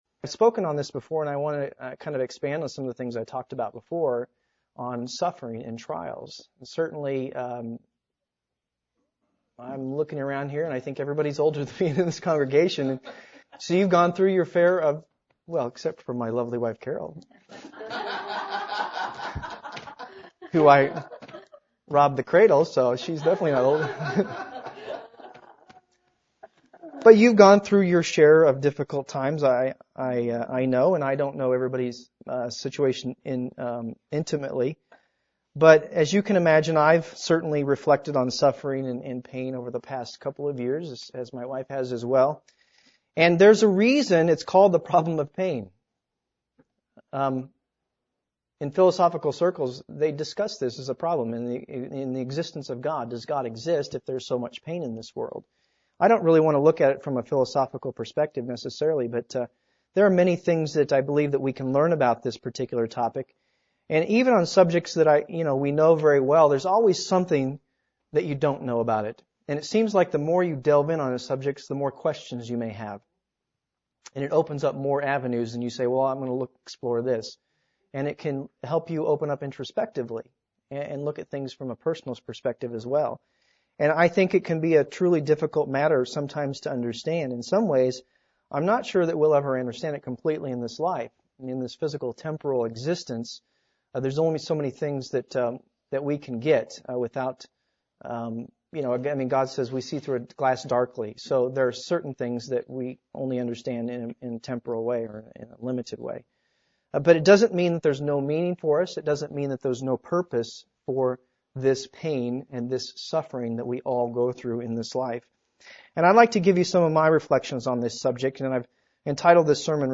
Sermons
Given in Columbia - Fulton, MO